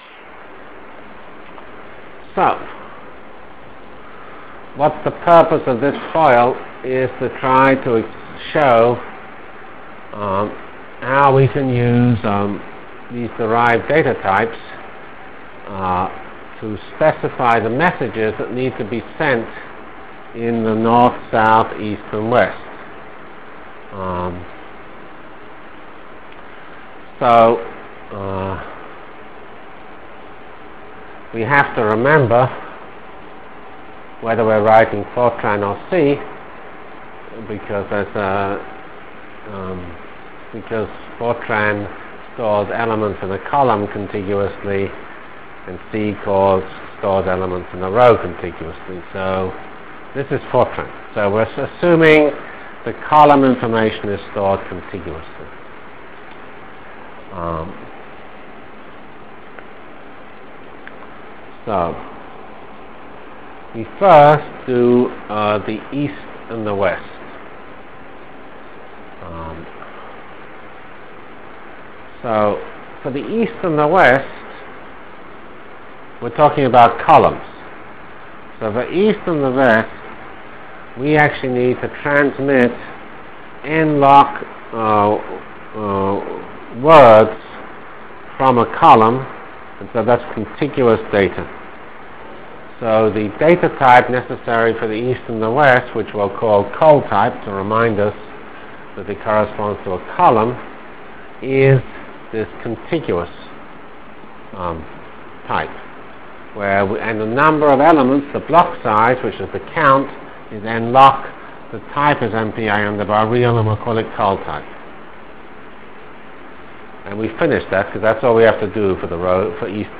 From CPS615-Completion of MPI foilset and Application to Jacobi Iteration in 2D Delivered Lectures of CPS615 Basic Simulation Track for Computational Science -- 7 November 96. by Geoffrey C. Fox